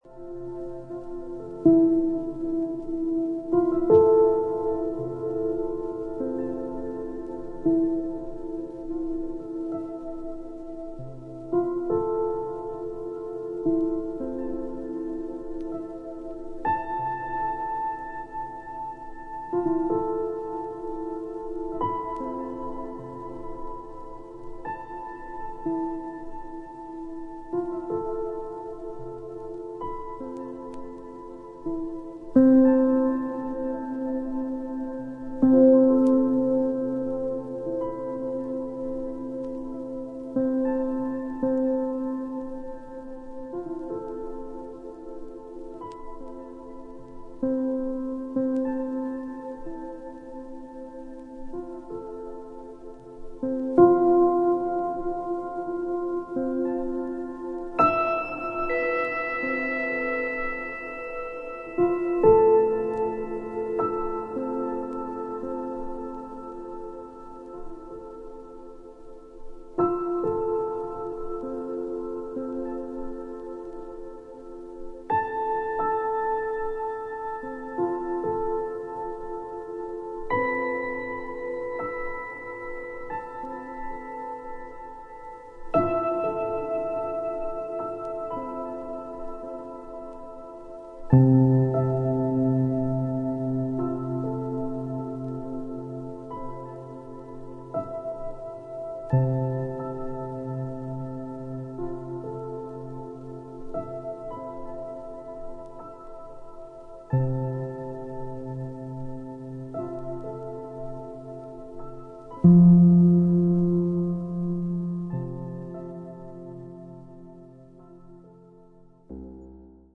ピアノやヴァイオリン、ヴィオラ、ムーグギター等、穏やかで温かみのある楽器の音色をベースに